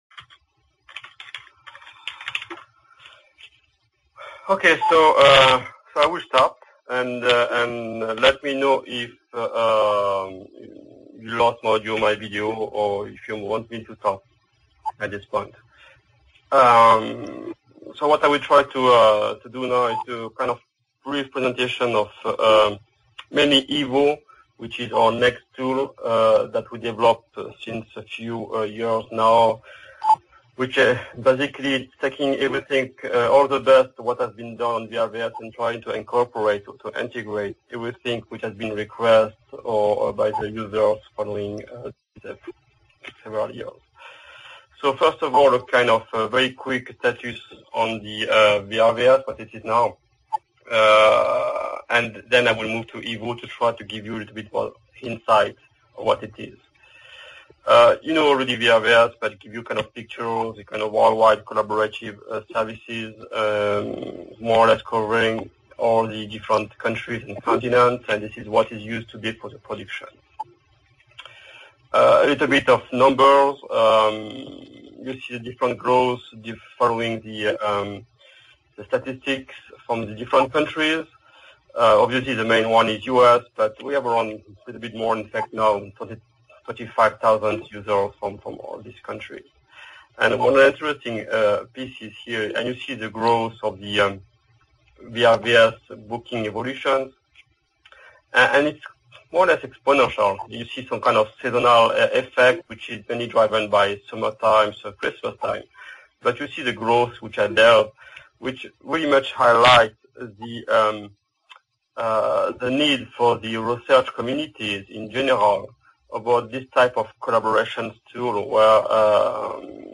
EFDA- Remote Participation Contacts Workshop, Riga, Latvia Kategóriák Alkalmazott informatika